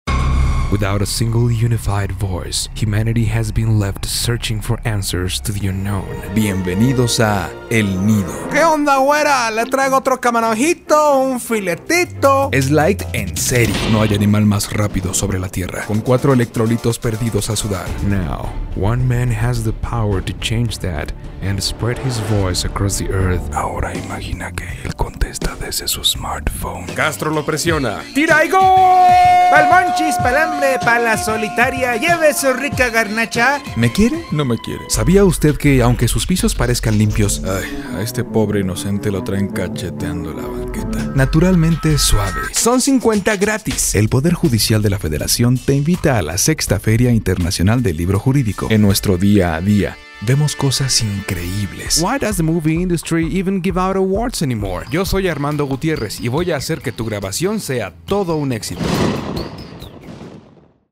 kastilisch
Sprechprobe: Werbung (Muttersprache):
spanish voice-over artist.